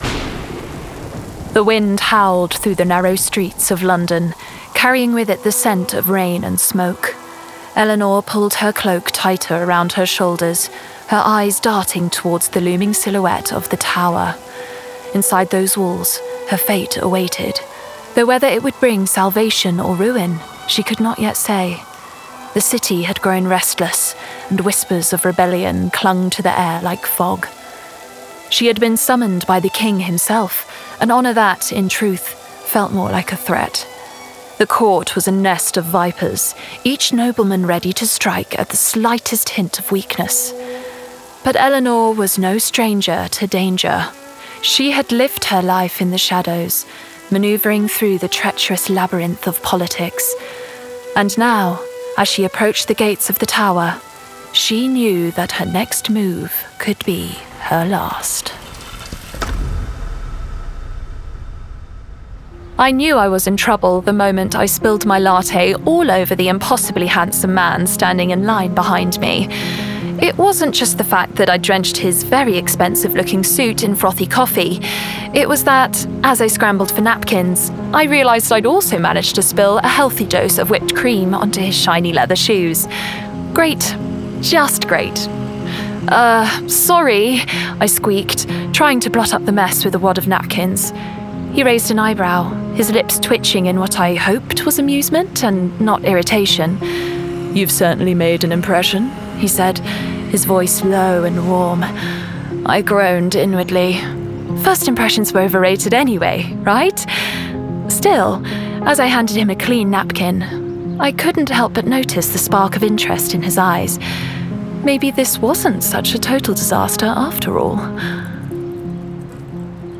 Audiobook Demo:
With a vocal profile that is resonant, sincere and a little cheeky, I offer a vibrant and youthful natural RP voice for commercials, video games, animated characters and audiobooks alike.
Resonant, warm, sincere, playful.
Modern British/ Received Pronunciation.